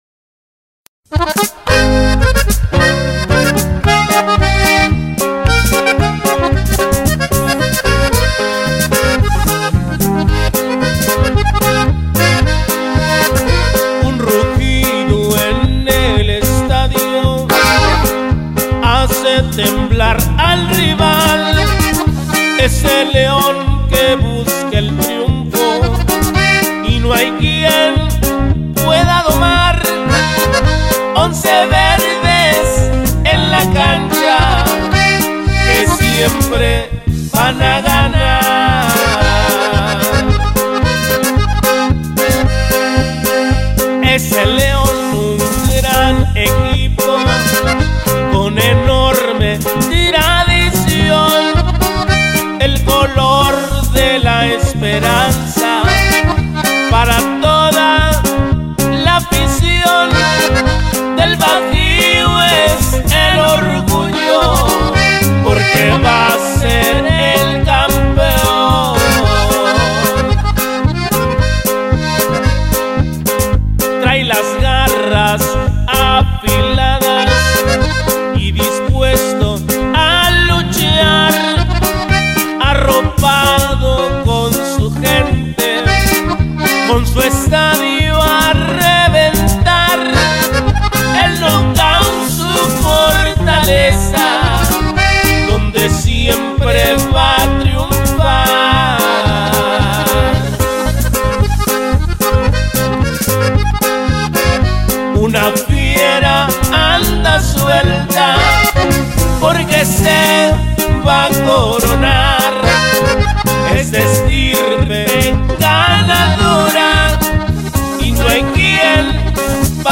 Buena